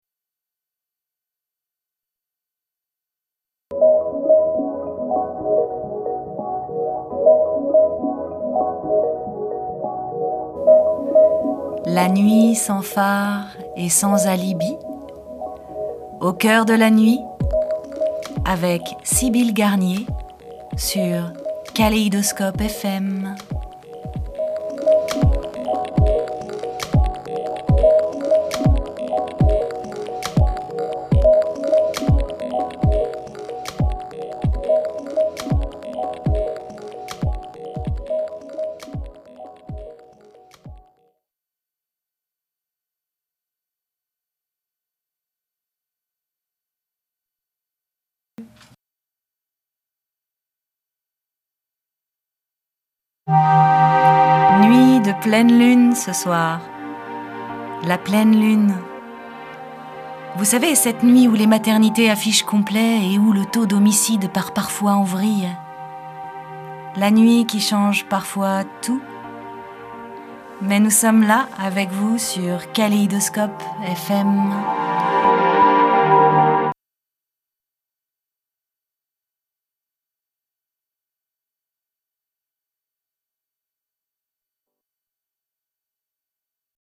voix radio